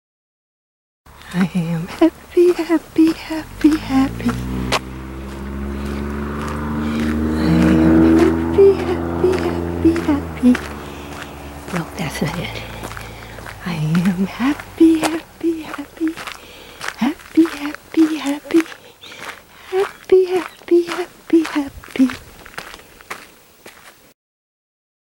The energies of the “I Am Happy” song are light and quick, but off tune.